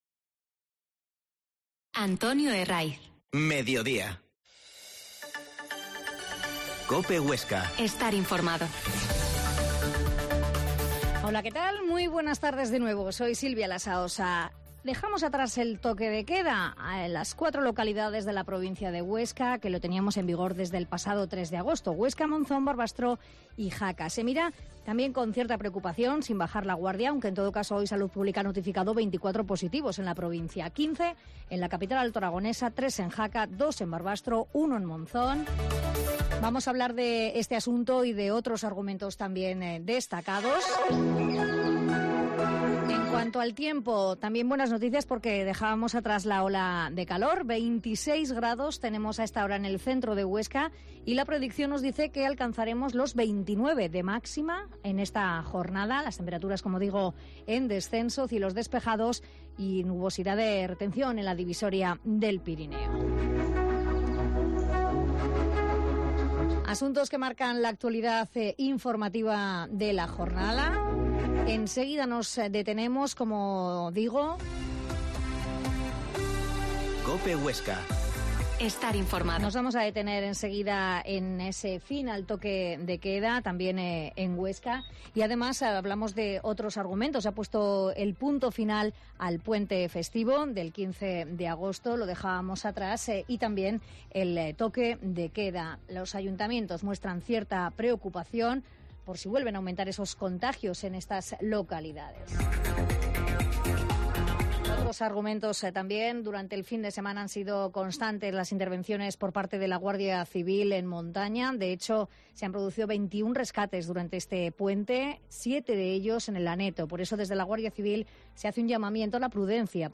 Mediodia en COPE Huesca 13.20h Entrevista al Teniente Alcalde de Huesca, José Mª Romance